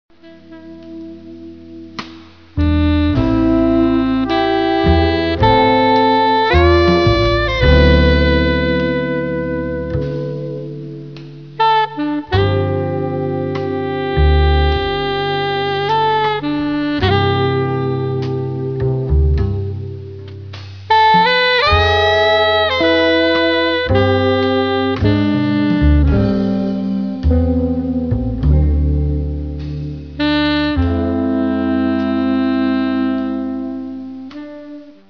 alto & soprano sax